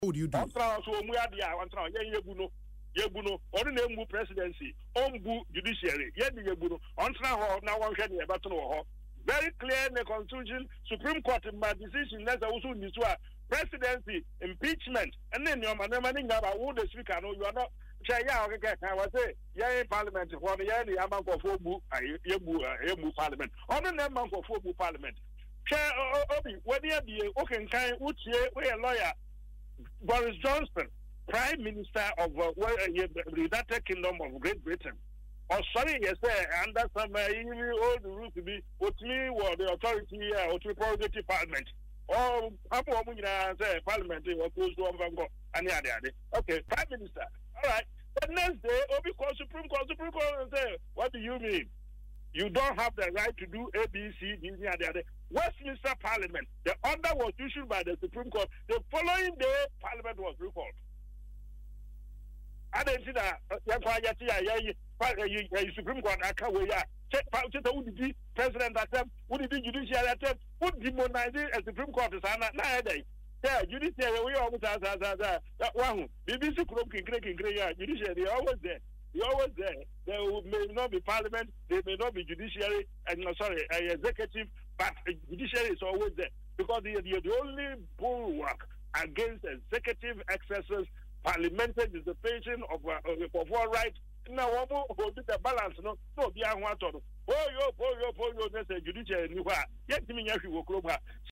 In an interview on Asempa FM’s Ekosii Sen, Hammond argued that the Speaker’s recent actions contribute to a loss of respect for Parliament, asserting that “it is not the MPs but the Speaker who is responsible” for any diminishing regard for the legislative body.